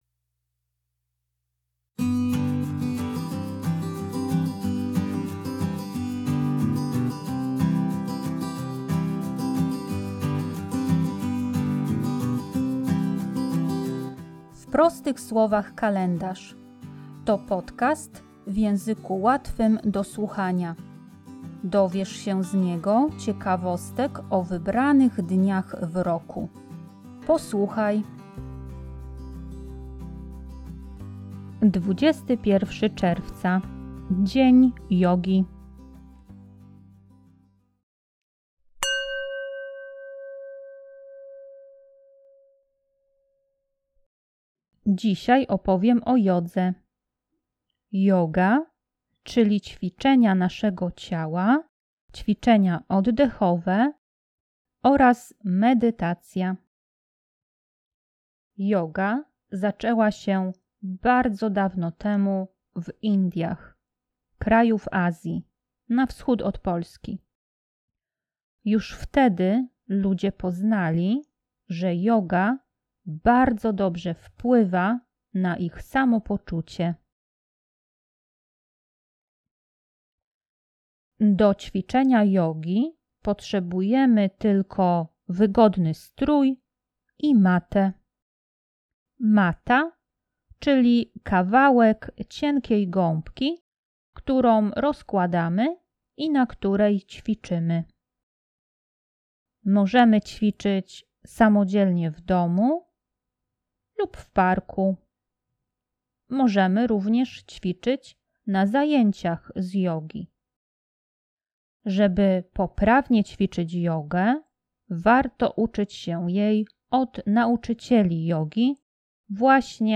W podcaście usłyszycie dźwięk gongu oraz muzykę relaksacyjną.